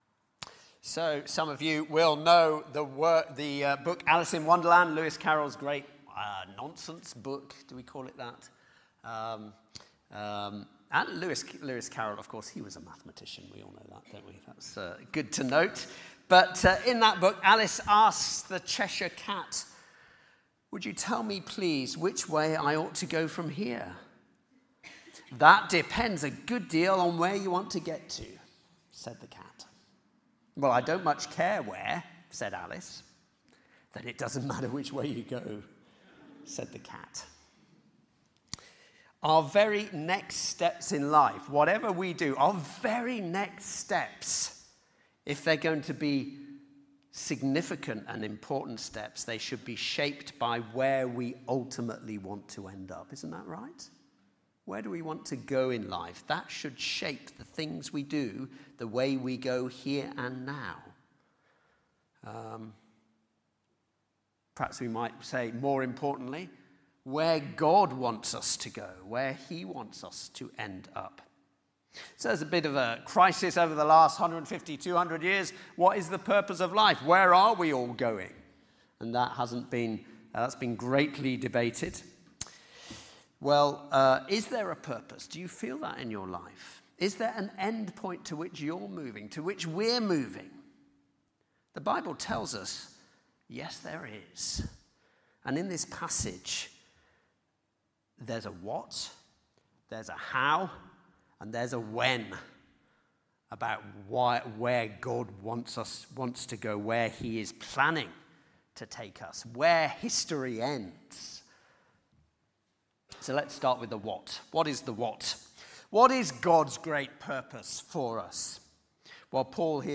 Sermon-8th-February-2026.mp3